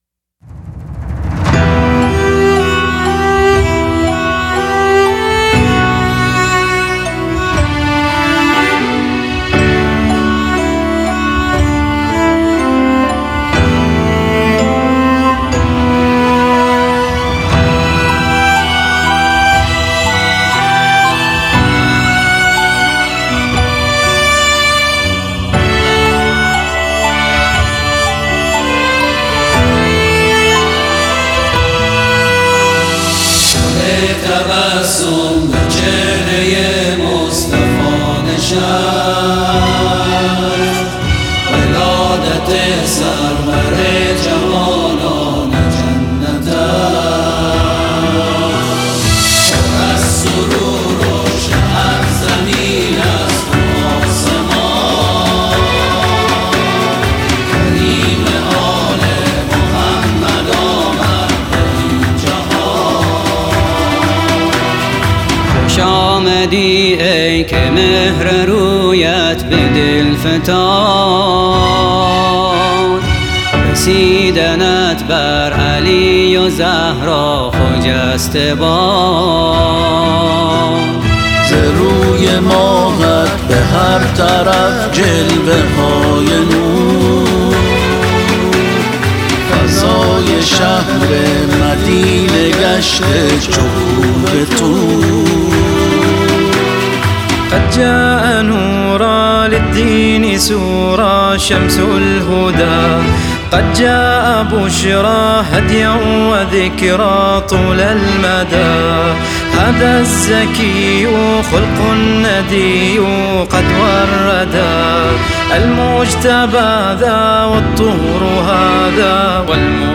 شعر آیینی